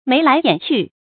注音：ㄇㄟˊ ㄌㄞˊ ㄧㄢˇ ㄑㄩˋ
眉來眼去的讀法